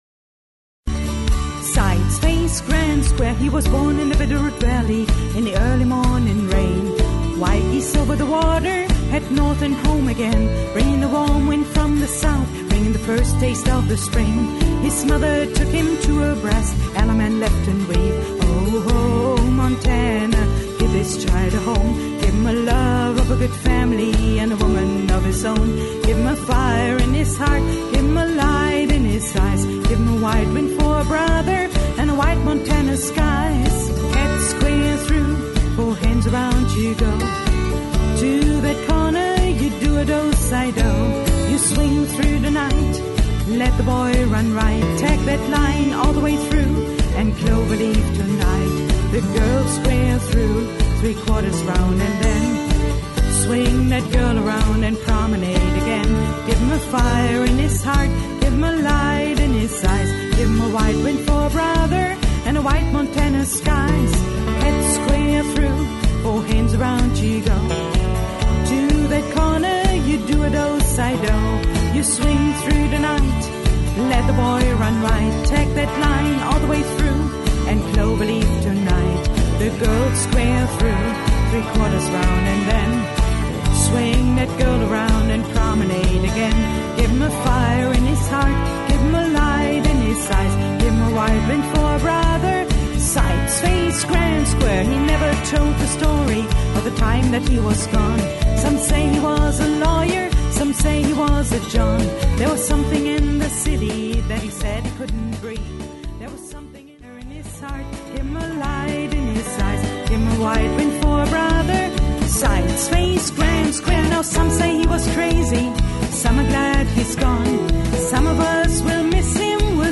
SA = Sing Along